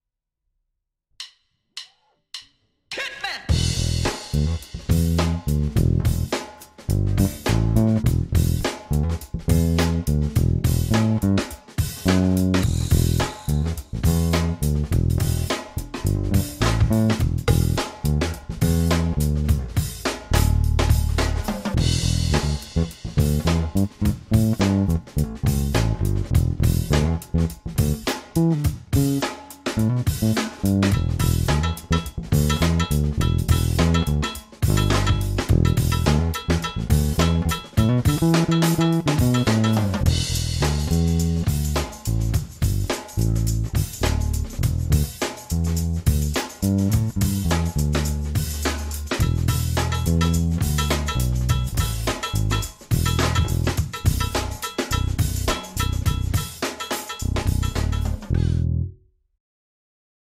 Krkový snímač